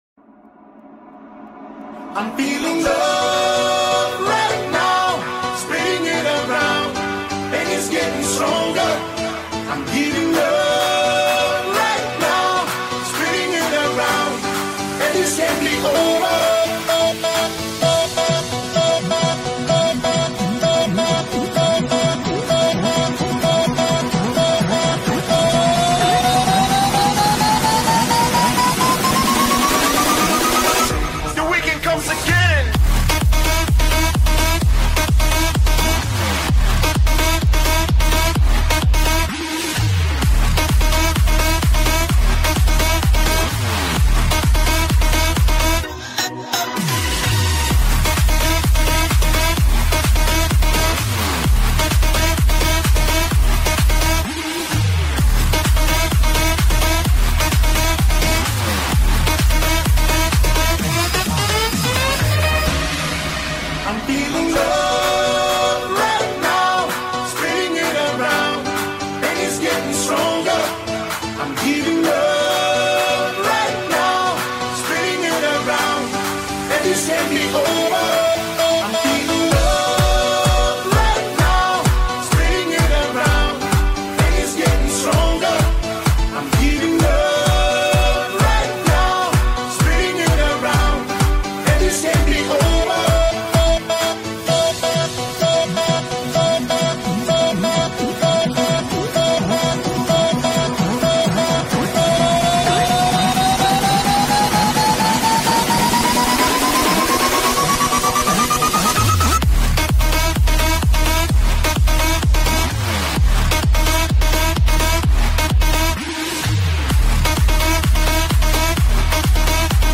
EDM Remake